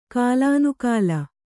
♪ kālānukāla